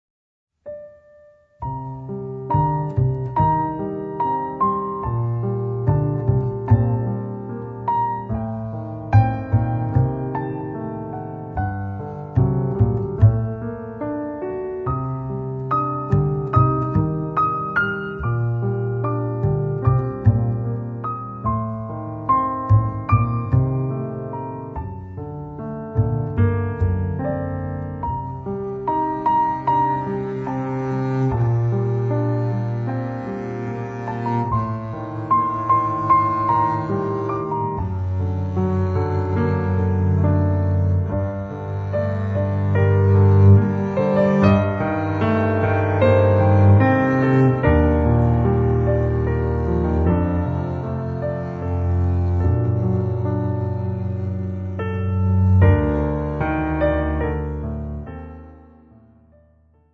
メロウで甘口、ジャーマン・ジャズ・ピアノ・トリオの2013年2ndアルバム